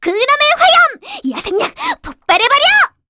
Cleo-Vox_Skill7_kr.wav